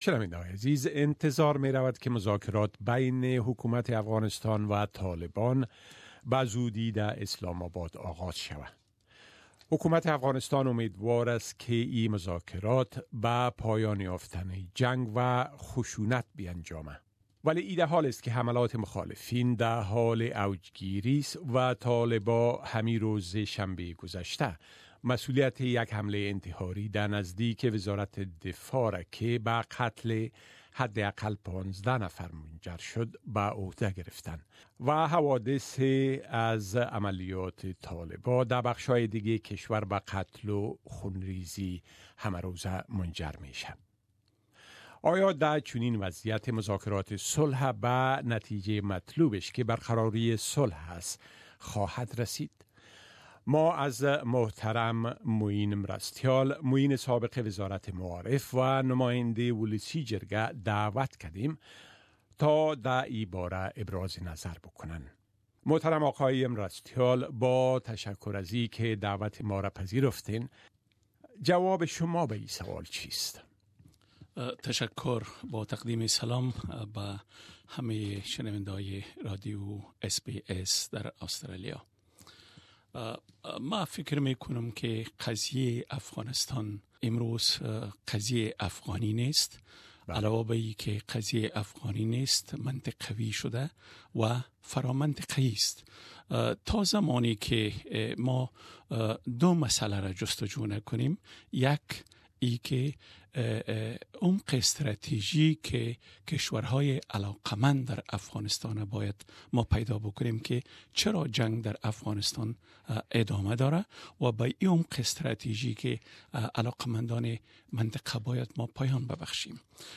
Interview with Former member of Afghan Parliament Mr Moin Marastial about Peace Prospects in Afghanistan